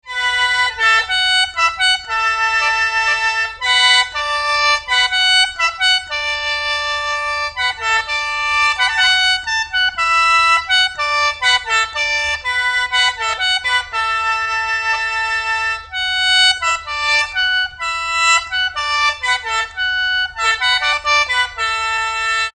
sheng.mp3